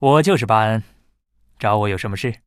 文件 文件历史 文件用途 全域文件用途 Bhan_tk_01.ogg （Ogg Vorbis声音文件，长度2.5秒，111 kbps，文件大小：34 KB） 源地址:游戏中的语音 文件历史 点击某个日期/时间查看对应时刻的文件。